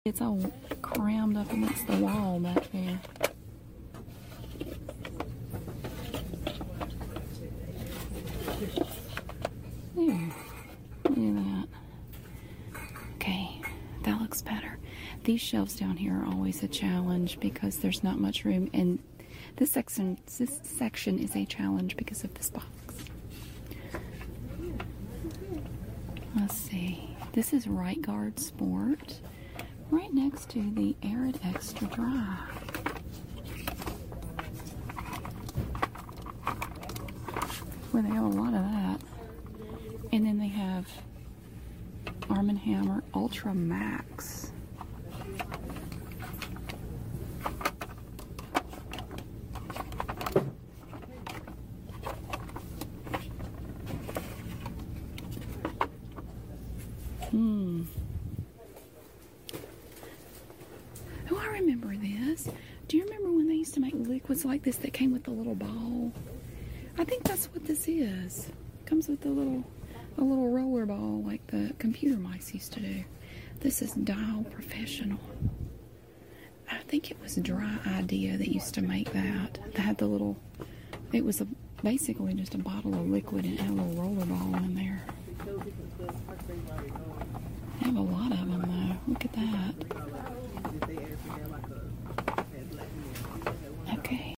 Southern women otganizez deodorant sound effects free download